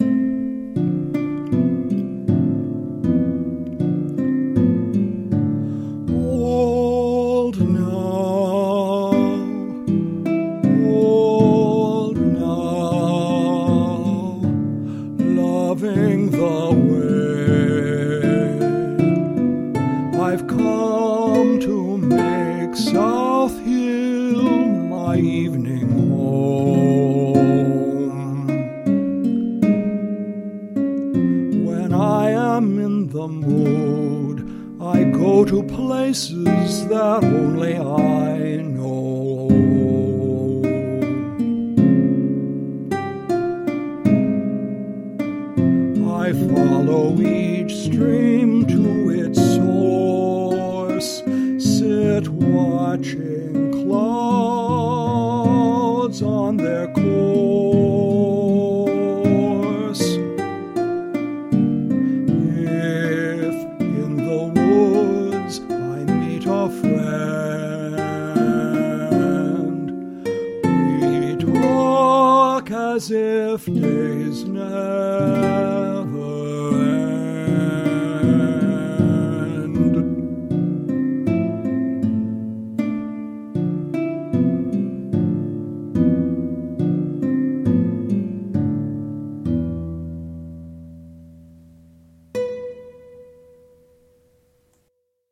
for solo voice and guitar
for solo voice and piano